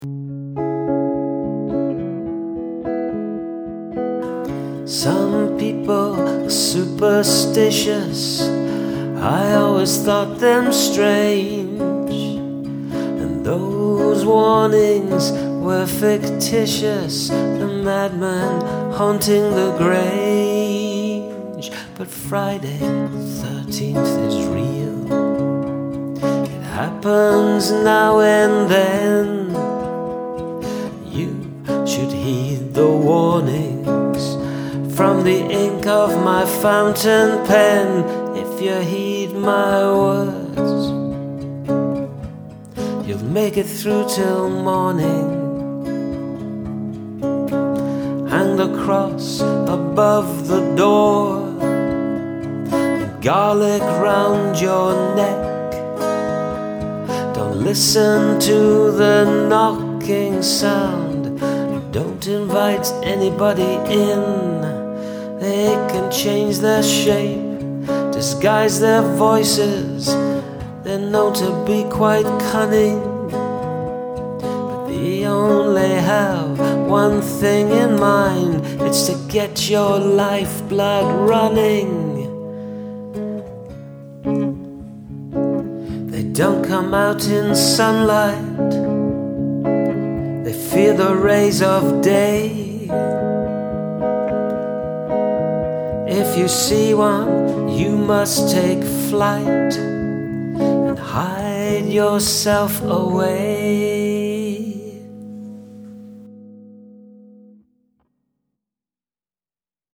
Very haunting and love the foreboding atmosphere it gives, great take on the skirmish.
Your vocals and playing really add to the warning and the sense of dread.
You did well creating a sense of dread.